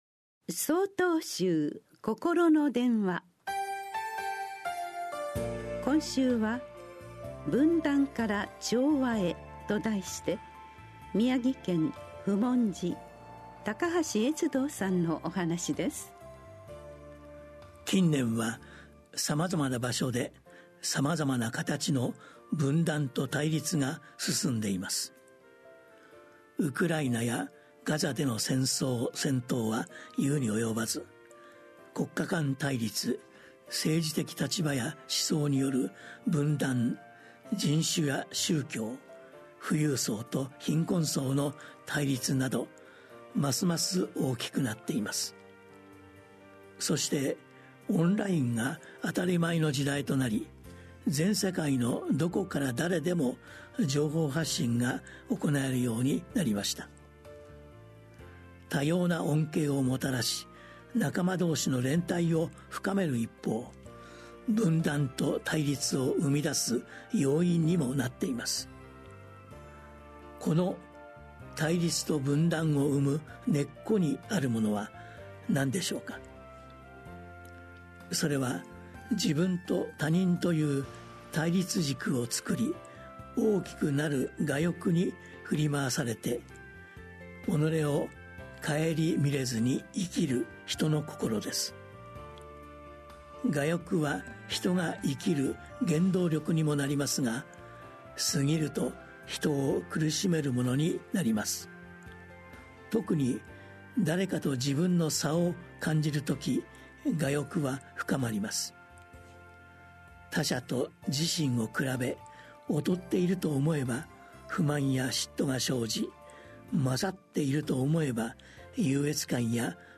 心の電話（テレホン法話）７/22公開『分断から調和へ』 | 曹洞宗 曹洞禅ネット SOTOZEN-NET 公式ページ
曹洞宗では毎週、わかりやすい仏教のお話（法話）を、電話と音声やポッドキャストにて配信しています。